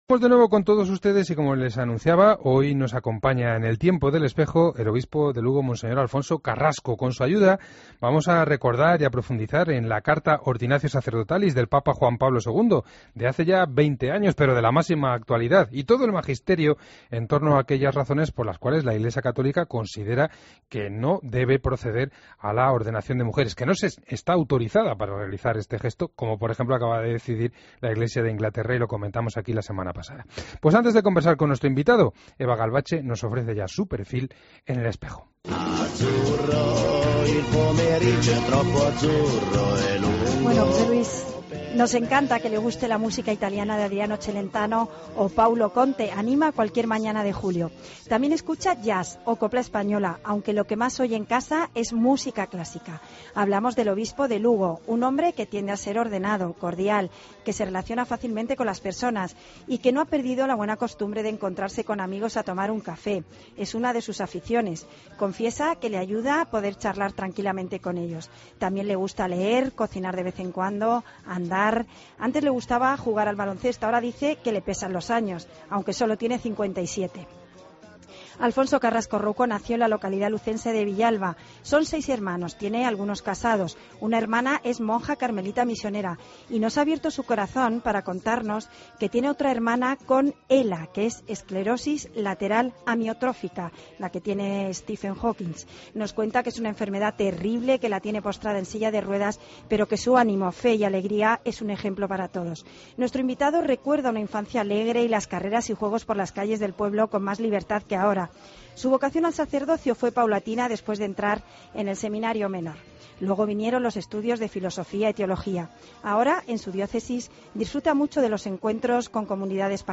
AUDIO: Escucha la entrevista completa a monseñor Alfonso Carrasco en 'El Espejo'